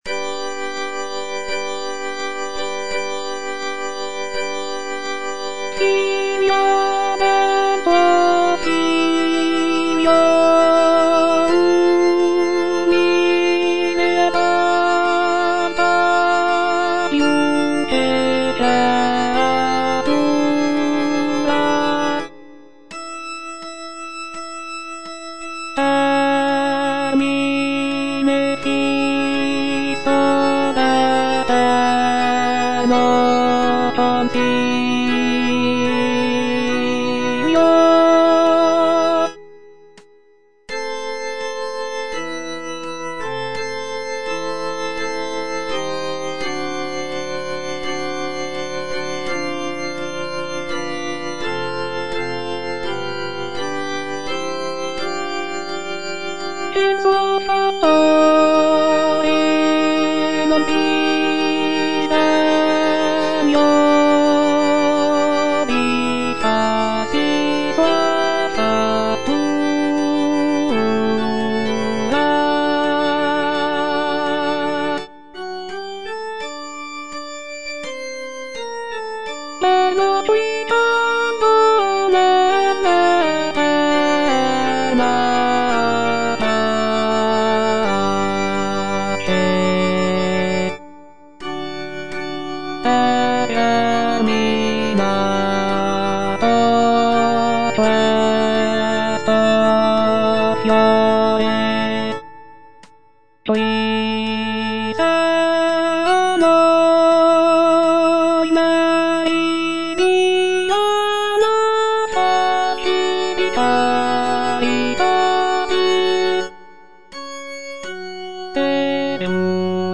G. VERDI - LAUDI ALLA VERGINE MARIA Alto II (Voice with metronome) Ads stop: auto-stop
It is a hymn of praise to the Virgin Mary, with text inspired by Dante's Divine Comedy. The piece features lush harmonies and intricate counterpoint, showcasing Verdi's mastery of choral writing.